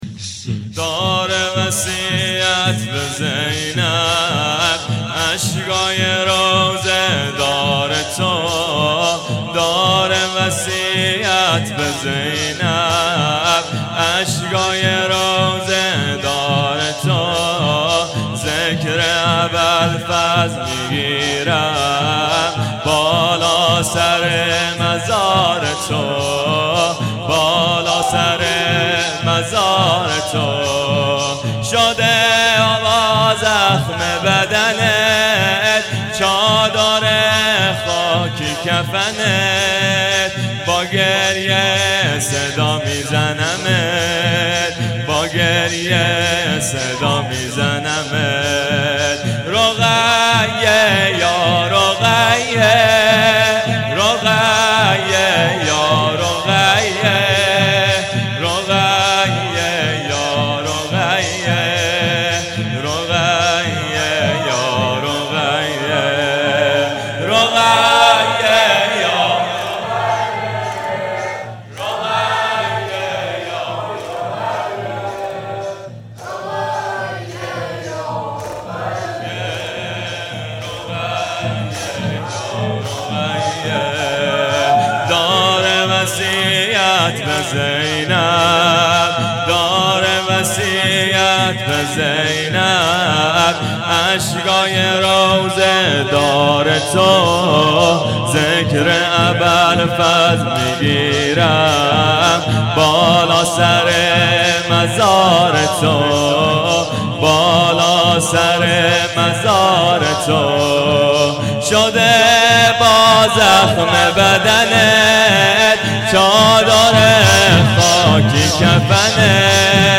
مکان:شهرک شهدا نبش پارک لاله هیئت علمدار سنقر